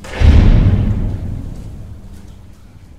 locked.ogg